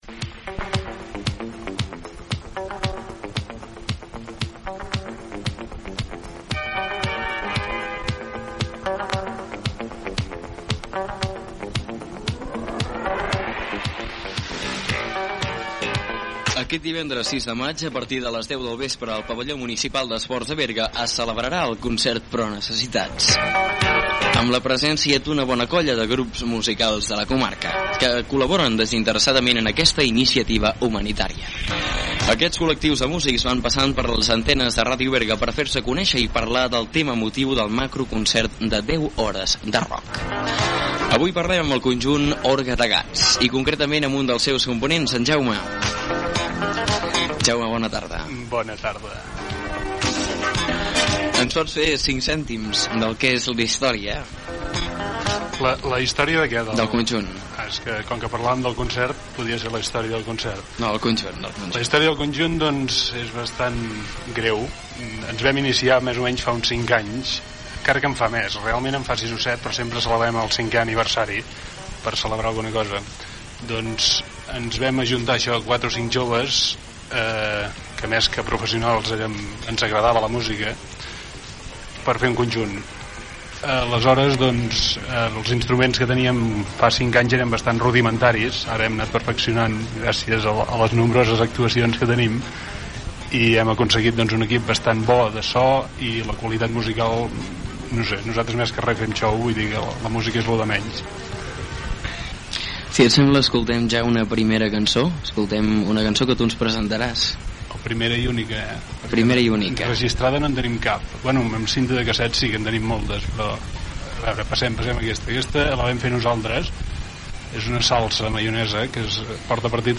Entrevista
Entreteniment
FM